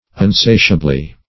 Unsatiable \Un*sa"ti*a*ble\, a.